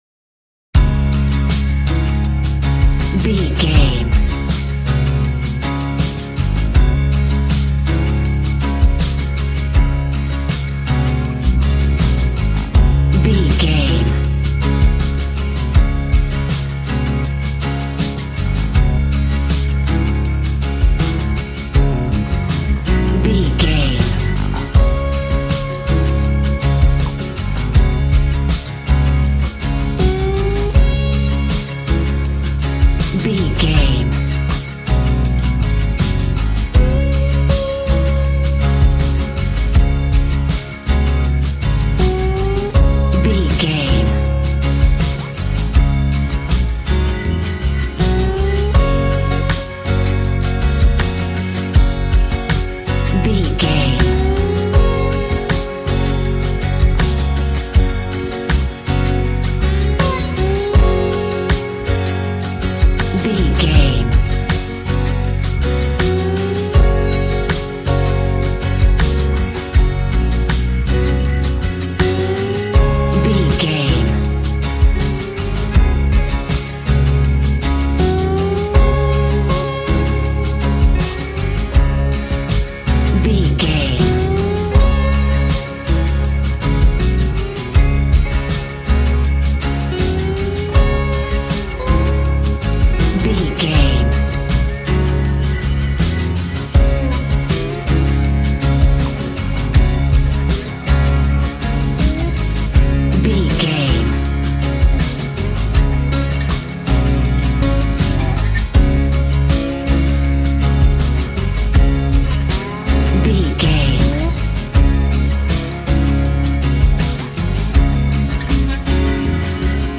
Ionian/Major
D♭
groovy
happy
electric guitar
bass guitar
drums
piano
organ